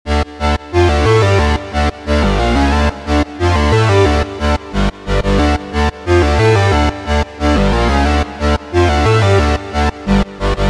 描述：180 BPM。
标签： 180 bpm Rave Loops Synth Loops 1.80 MB wav Key : C
声道立体声